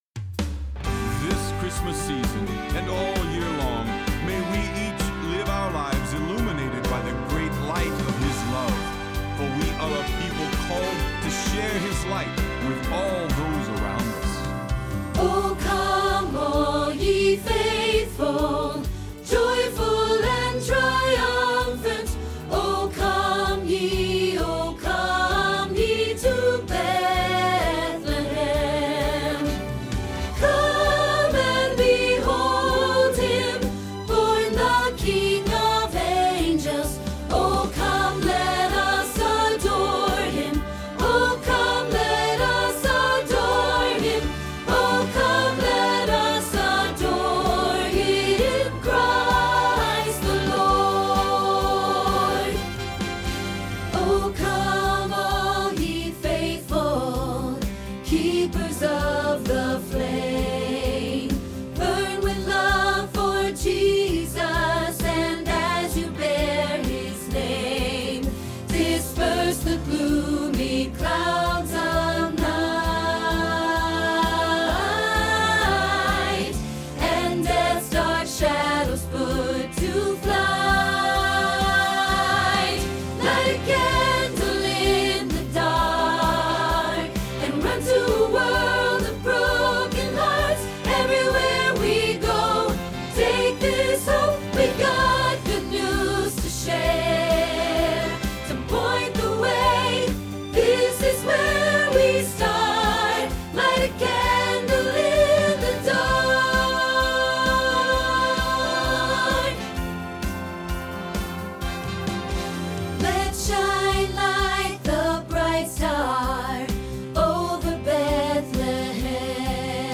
Soprano Practice Trax